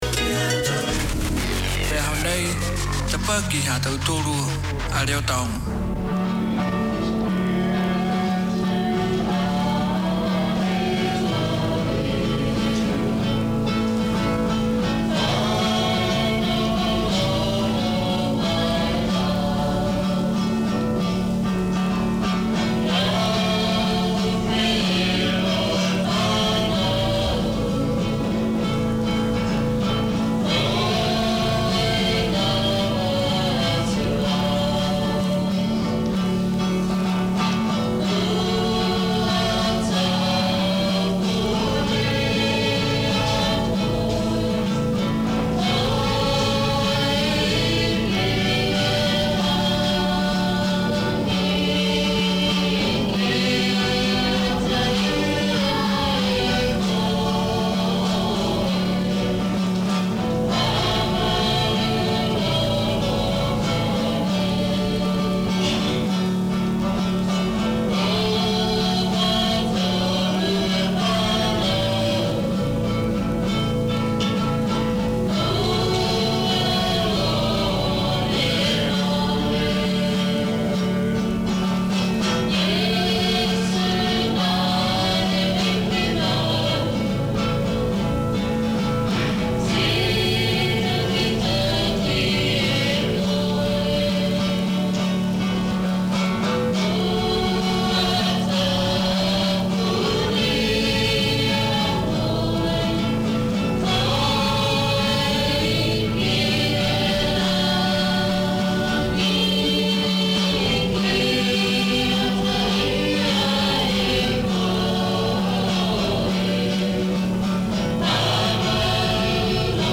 This is co-operative airtime shared between three Niuean Christian churches from around the Auckland region. The churches come to your place with a weekly rotation of services including preaching, singing, playing and praying. There are gospel songs and gospel lessons, praise and testifying.